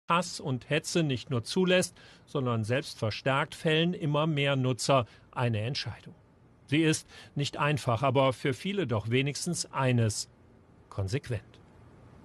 ZDF Journalist Elmar Theveßen animiert die Zuschauer im ZDF dazu, ihren Twitter Account zu löschen.